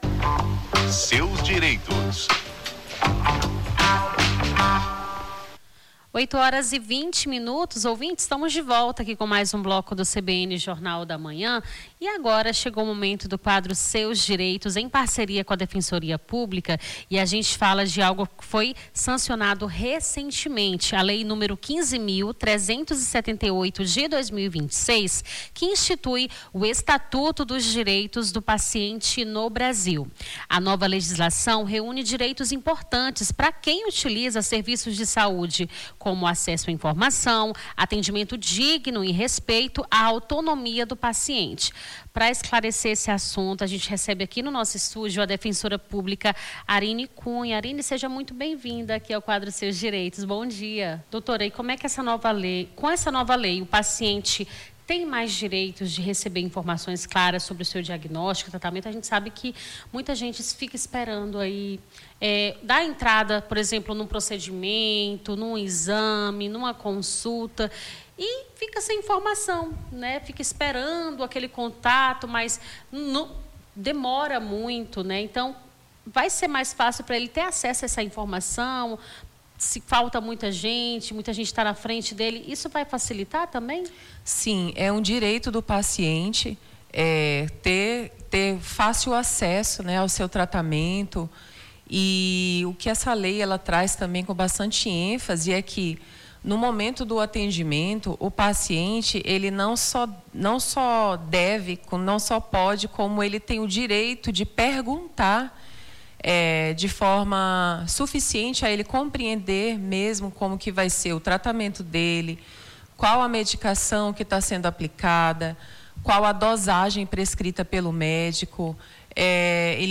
Na manhã desta segunda-feira, 21, conversamos com a defensora pública